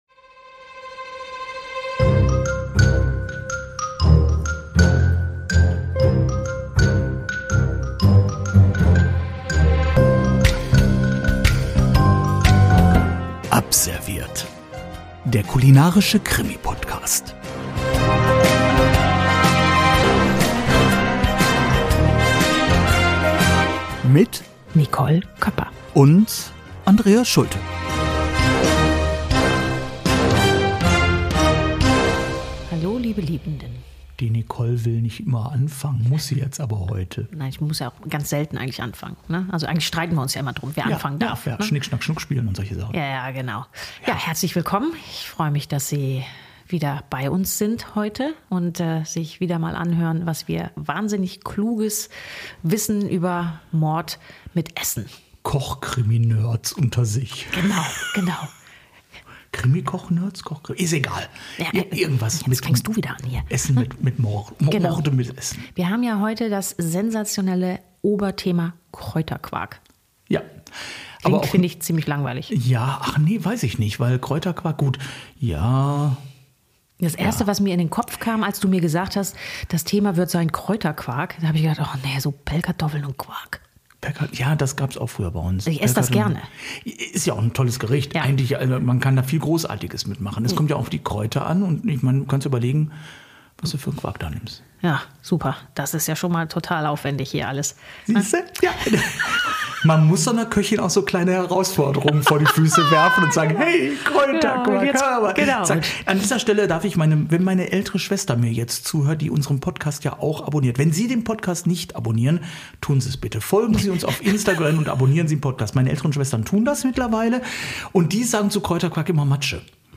quaken, pardon, plaudern heute über das mörderische Potenzial von Kräuterquark. Und natürlich gibt es dazu die passende Geschichte...